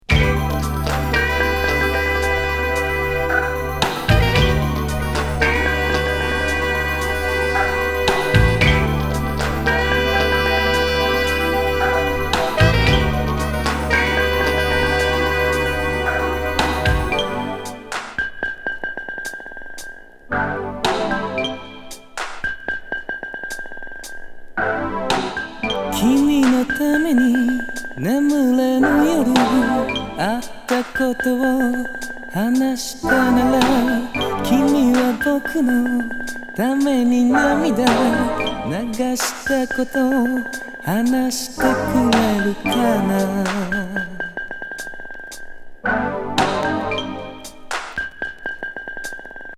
ビー玉転がり的極上サイケ・メロウ・バレアリックB面